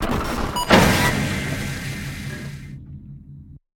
DoorOpen079.ogg